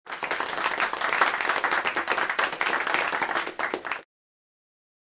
applause-BB7eiHab.mp3